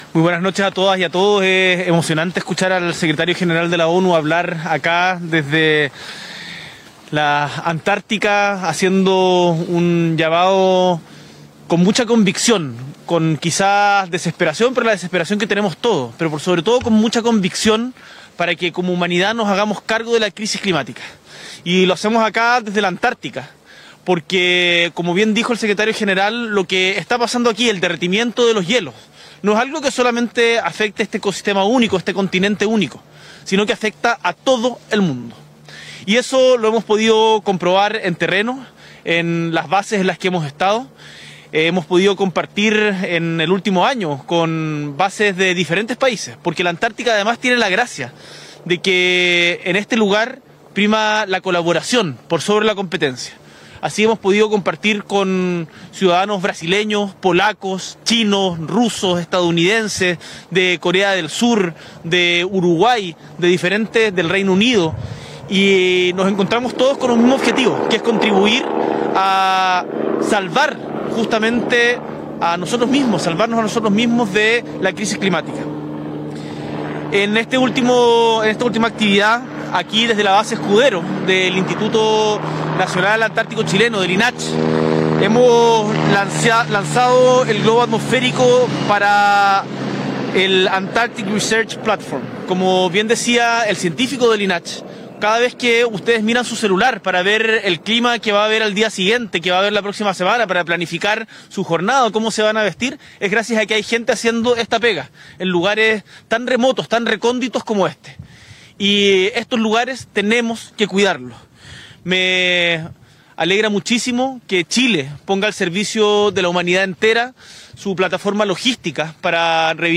Presidente de la República, Gabriel Boric Font, participa en el lanzamiento de radiosonda en Territorio Antártico Chileno.